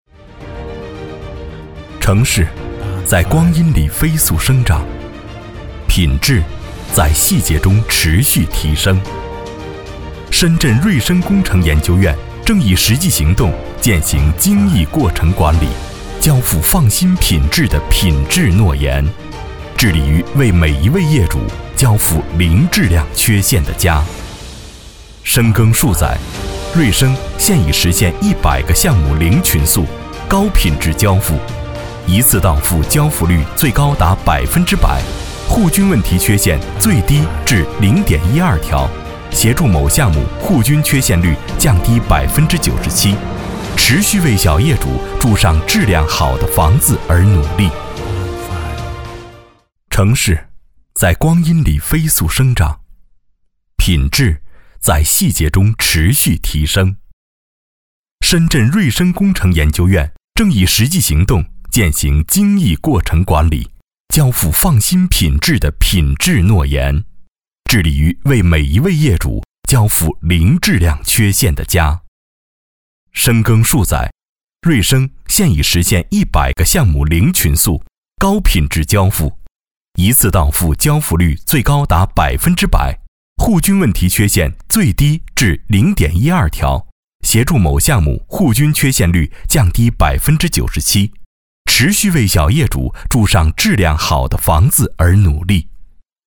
17 男国463_专题_企业_深圳瑞生工程研究院样音_浑厚 男国463
男国463_专题_企业_深圳瑞生工程研究院样音_浑厚.mp3